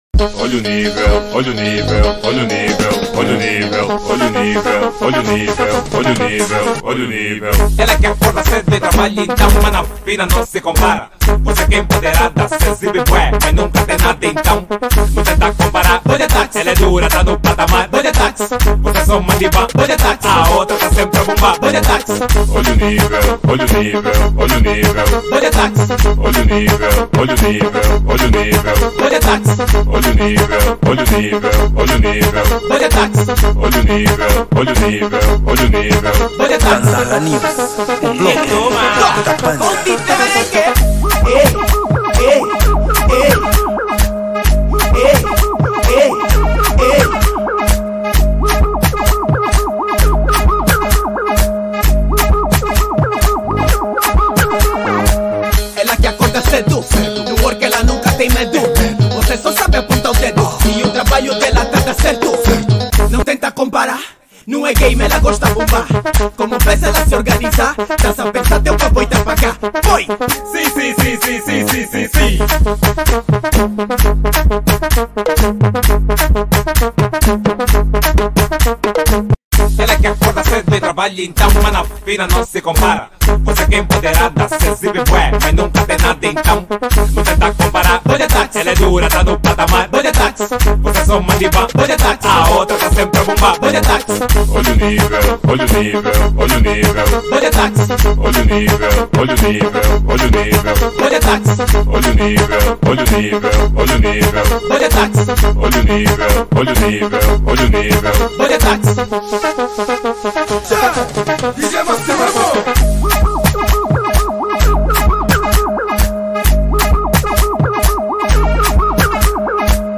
Gênero : Afro House